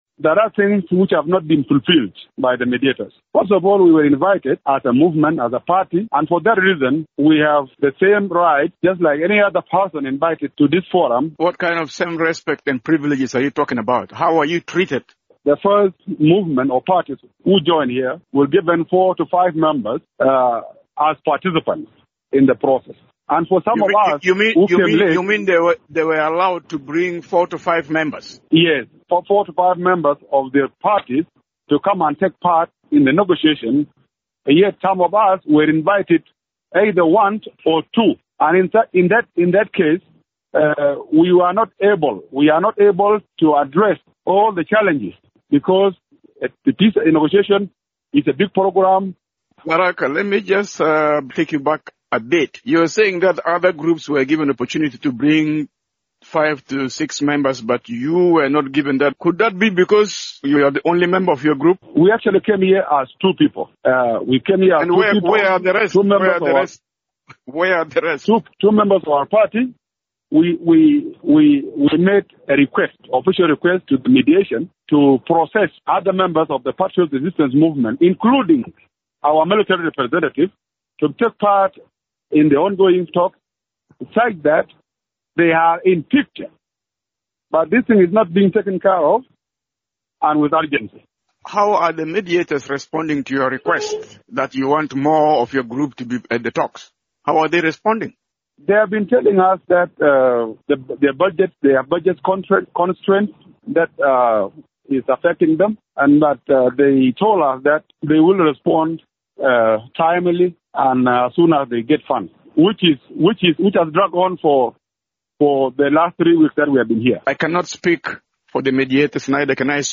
He spoke from Nairobi